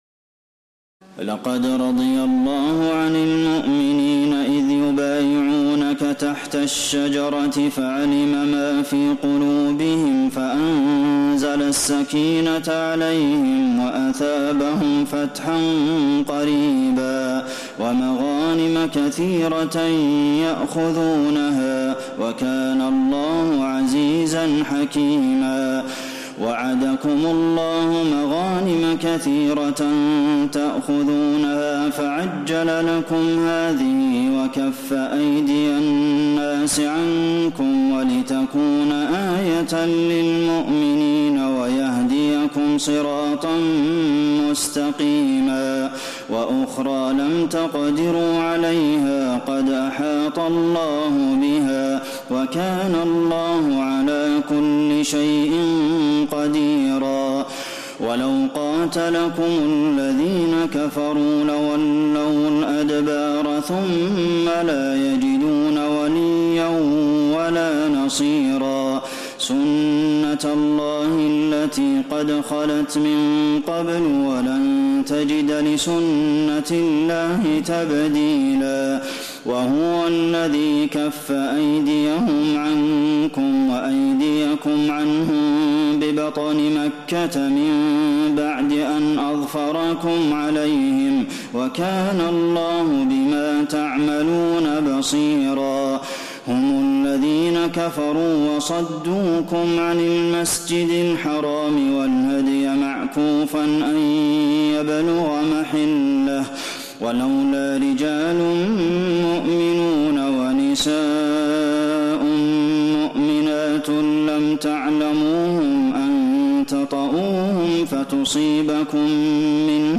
تراويح ليلة 25 رمضان 1432هـ من سور الفتح (18-29) الحجرات و ق Taraweeh 25 st night Ramadan 1432H from Surah Al-Fath and Al-Hujuraat and Qaaf > تراويح الحرم النبوي عام 1432 🕌 > التراويح - تلاوات الحرمين